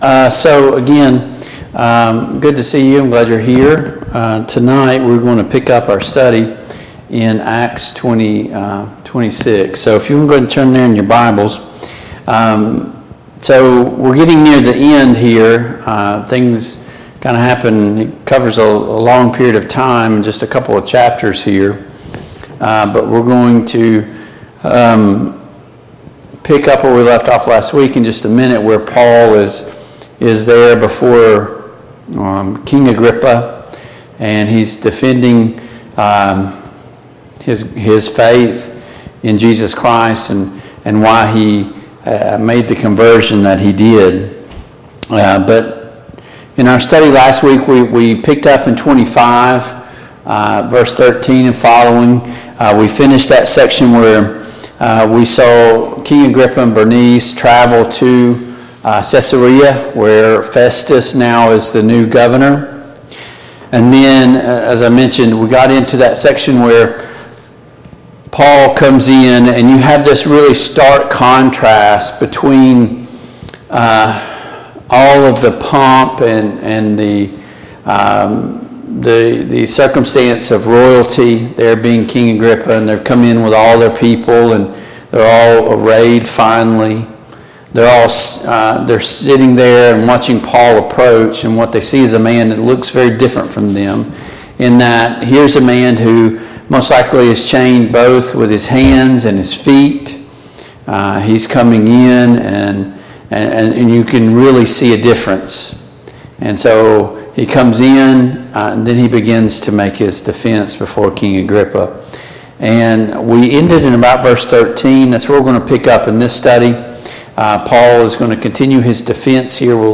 Passage: Acts 26:19-32 Service Type: Mid-Week Bible Study